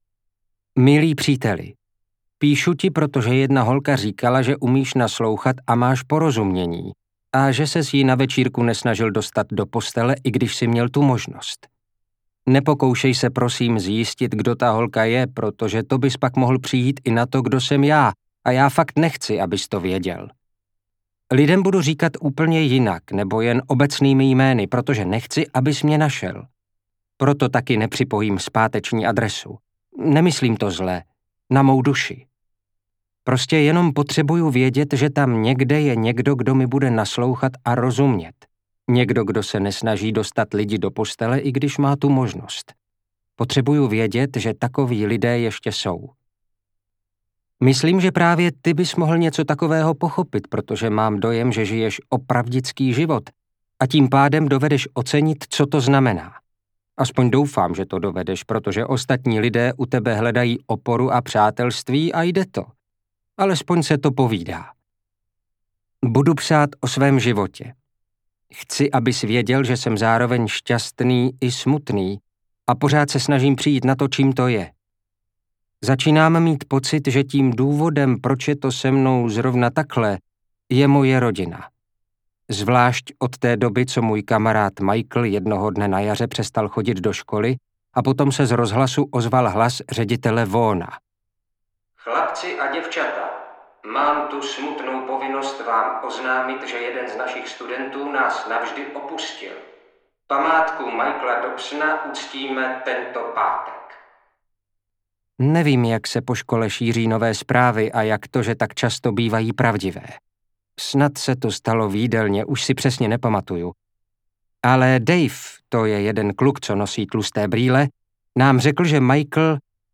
Ten, kdo stojí v koutě audiokniha
Ukázka z knihy
ten-kdo-stoji-v-koute-audiokniha